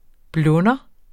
Udtale [ ˈblɔnʌ ]